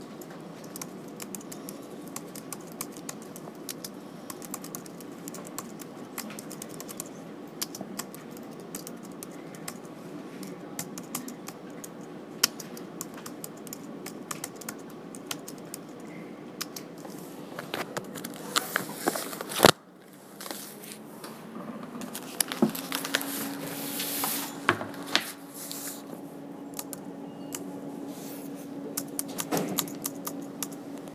Field Recording #3
A 30 second sound byte of me typing and looking through my notes trying to complete a task for Hofstra. This happened Wednesday at 2:00 PM inside the Hofstra library.
Typing-1.mp3